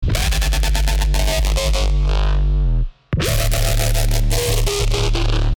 ❇ Ready for BASS HOUSE, TRAP, UKG, DRUM & BASS, DUBSTEP and MORE!
FG - So Slammed 1 [Dmin] 174BPM
FG-So-Slammed-1-Dmin-174BPM.mp3